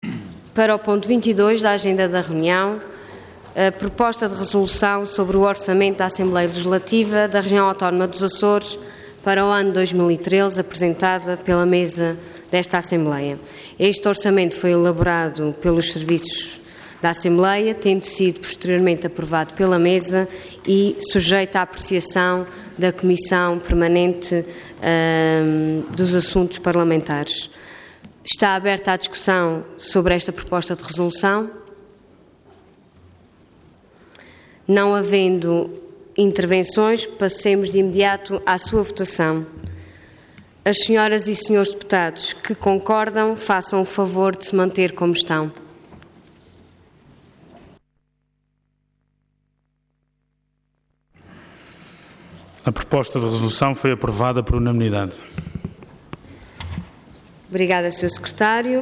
Intervenção Proposta de Resolução Orador Ana Luísa Luís Cargo Presidente da Assembleia Regional Entidade Mesa da Assembleia